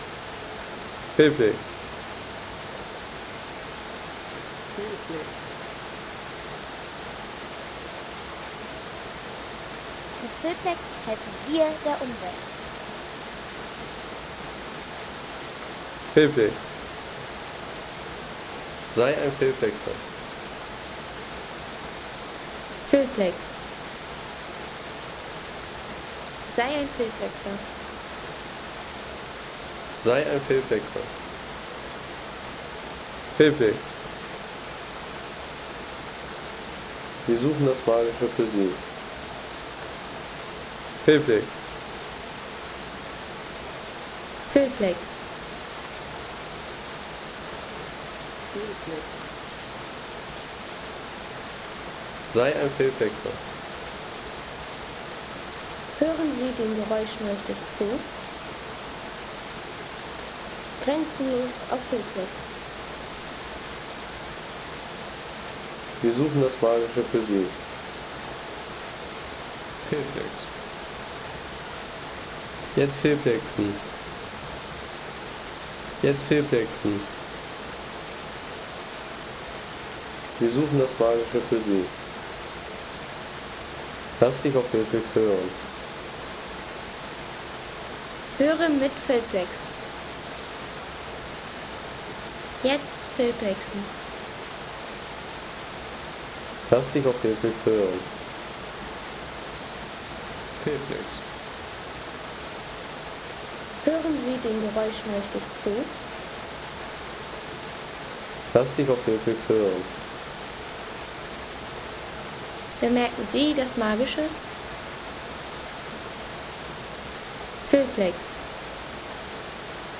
Wasserfallkraft Lotenbach – Naturaufnahme aus der Wutachschlucht
Erleben Sie den tosenden Klang des Lotenbach-Wasserfalls – eine wilde Soundaufnahme direkt aus der Lotenbachklamm im Schwarzwald.
Eine kraftvolle Tonaufnahme vom großen Lotenbach-Wasserfall in der Wutachschlucht – tosende Wasserklänge, Waldluft und natürliche Frische.